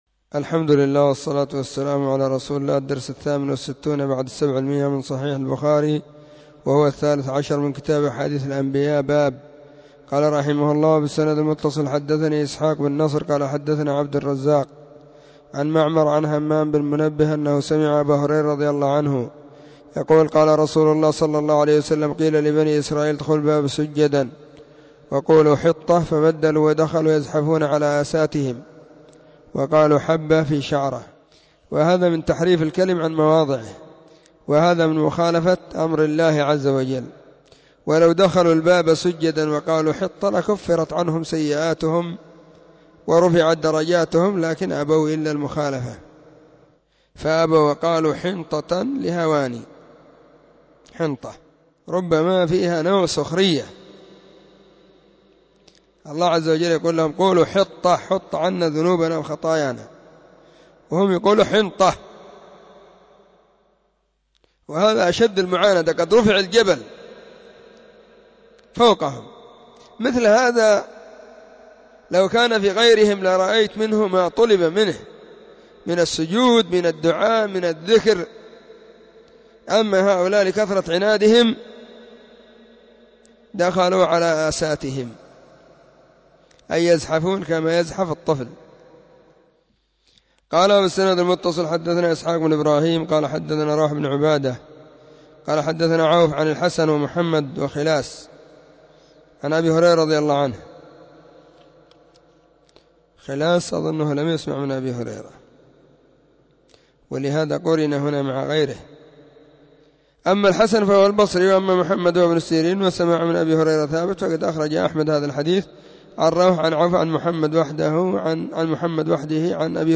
🕐 [بين مغرب وعشاء – الدرس الثاني]
كتاب-أحاديث-الأنبياء-الدرس-13.mp3